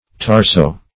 Meaning of tarso-. tarso- synonyms, pronunciation, spelling and more from Free Dictionary.